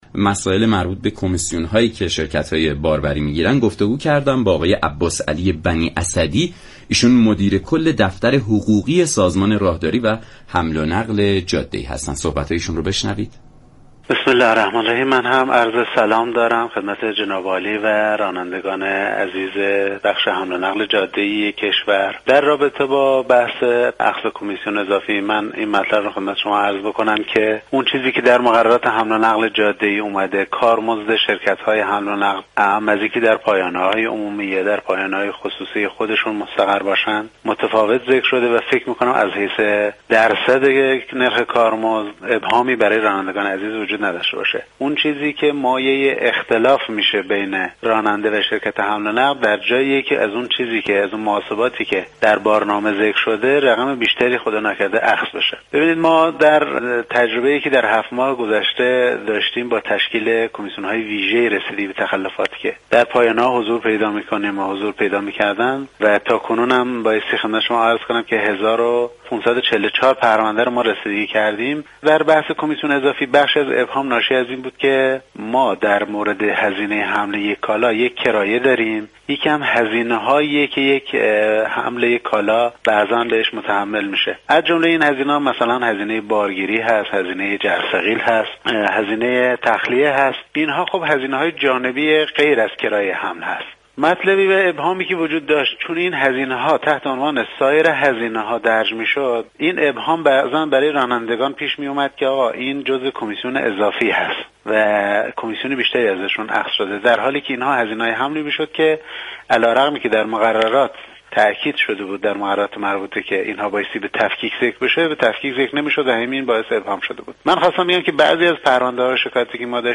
عباسعلی بنی اسدی مدیر كل دفتر حقوقی سازمان راهداری و حمل و نقل جاده ای در بخش صفحه من